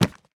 Minecraft Version Minecraft Version snapshot Latest Release | Latest Snapshot snapshot / assets / minecraft / sounds / block / chiseled_bookshelf / pickup1.ogg Compare With Compare With Latest Release | Latest Snapshot
pickup1.ogg